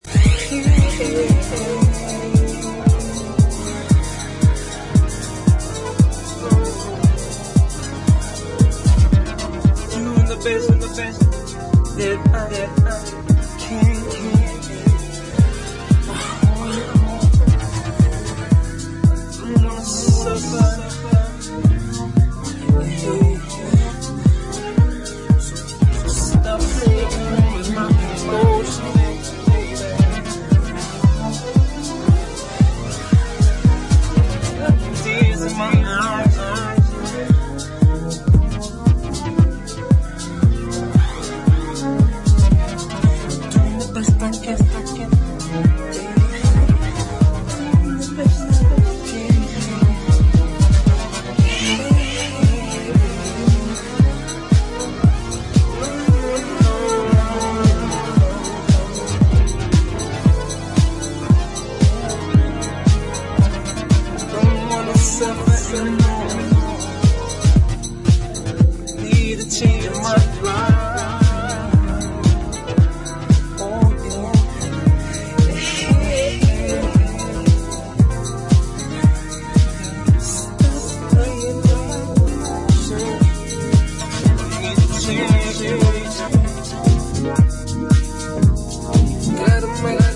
soulful forward thinking house music
House